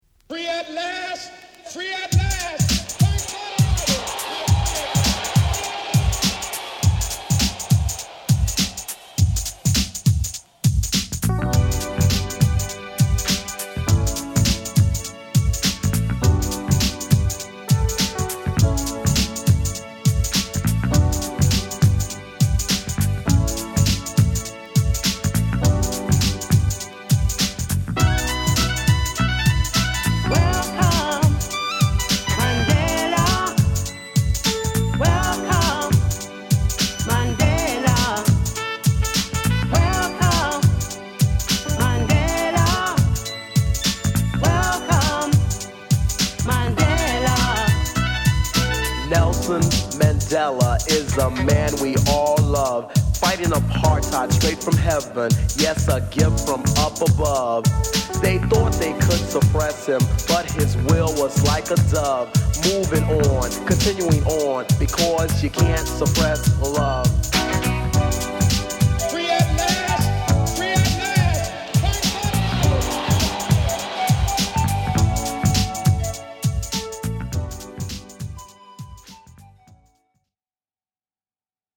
【希少マイナーR&B】美再生の良品!!
ちょっぴりNJSテイストなトラックにソウルフルな女性ヴォーカル